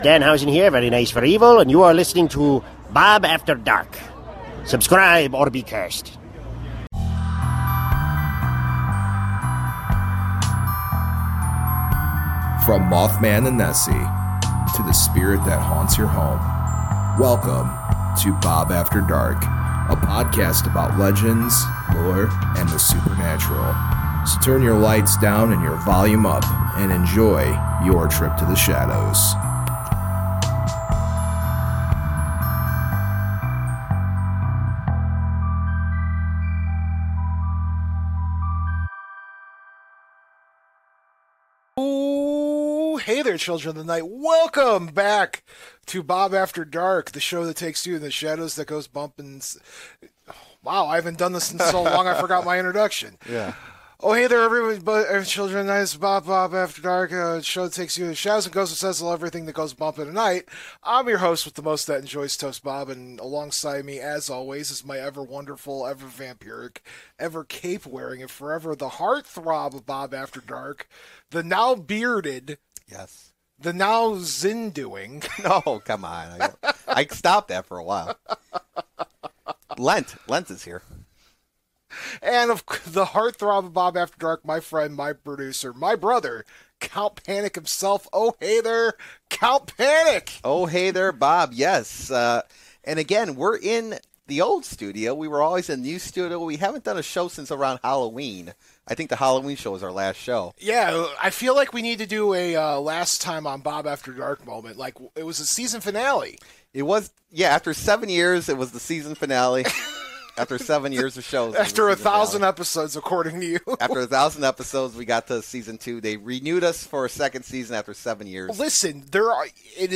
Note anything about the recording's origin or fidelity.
Well, we're in our new/old home at the old WJOB studios, and it feels amazing to be back!